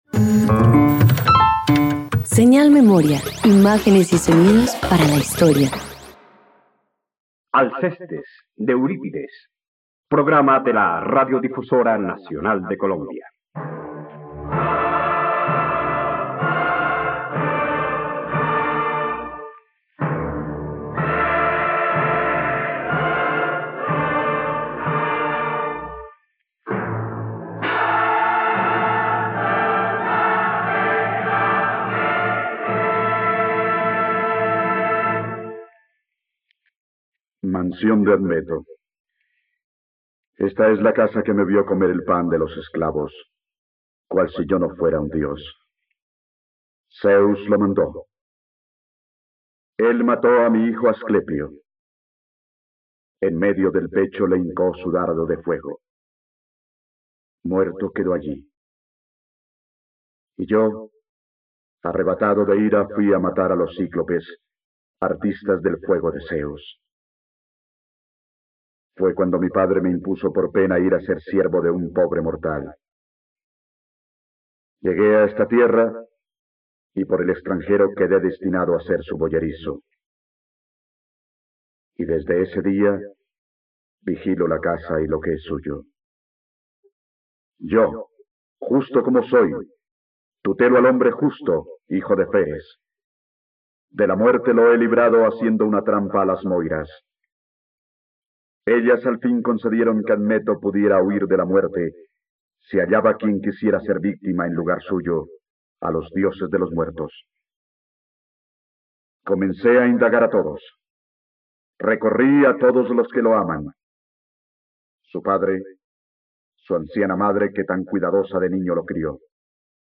Alcestes - Radioteatro dominical | RTVCPlay